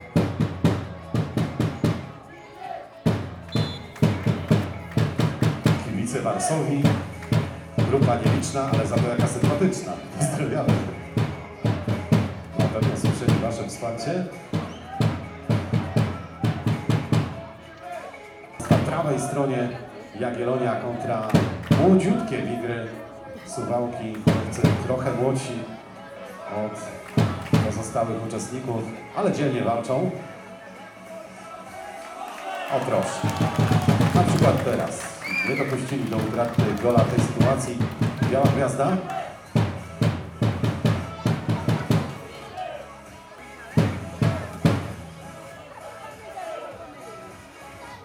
Głośno było na trybunach, młodych piłkarzy dopingowano z entuzjazmem i zaangażowaniem:
odglosy.wav